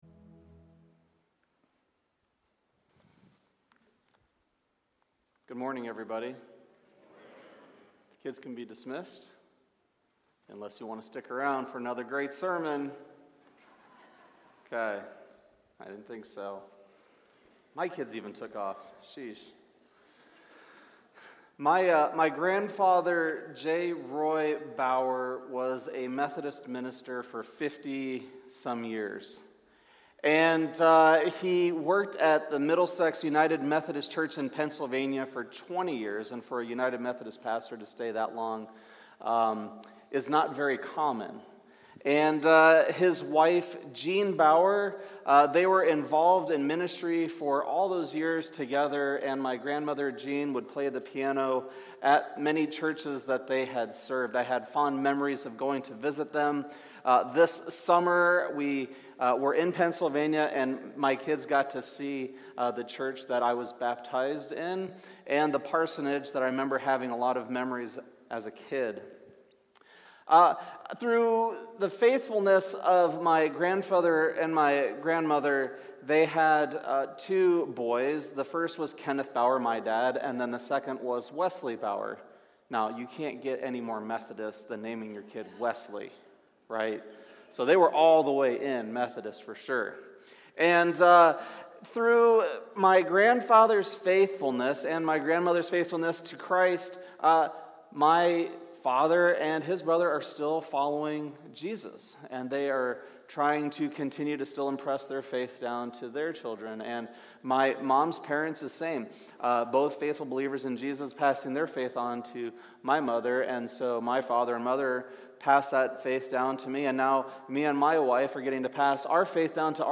Sermons | Countryside Covenant Church